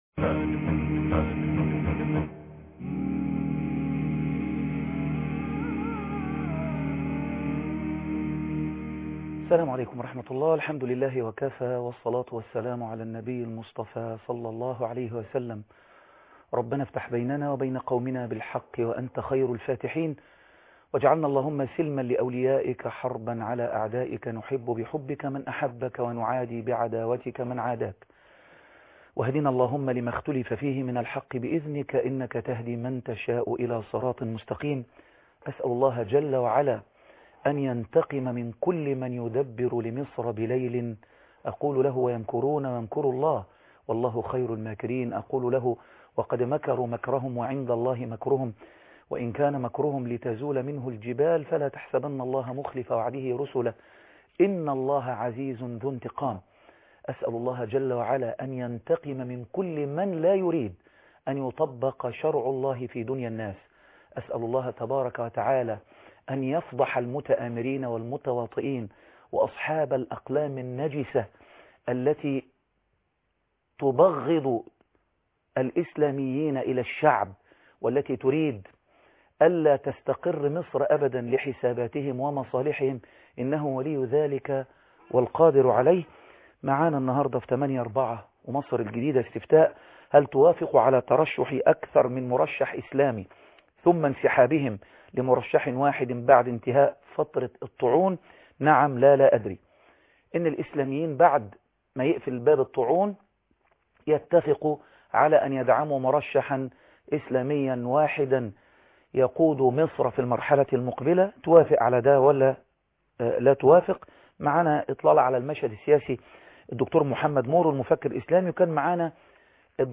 لقاء مع المفكر محمد مورو فى الاحداث الجارية ، الحالة النفسية للمصريين ( 8/4/2012 ) مصر الجديدة - الشيخ خالد عبد الله